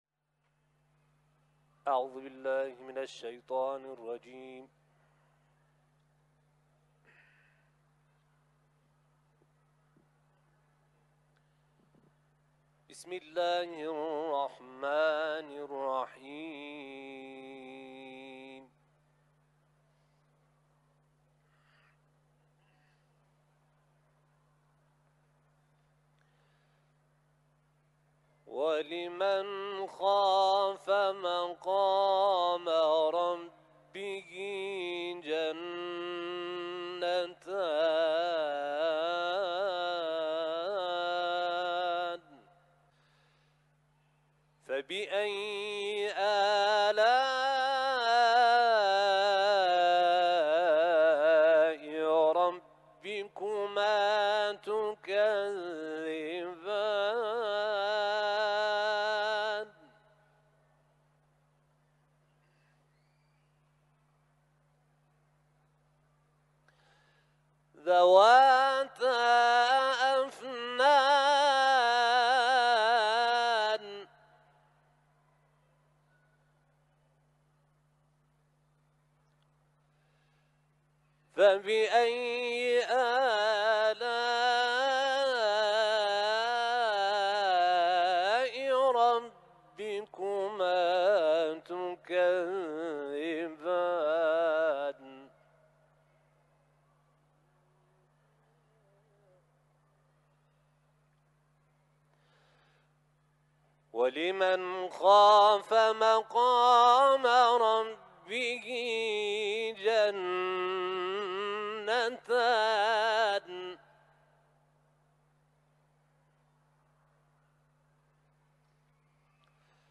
برچسب ها: تلاوت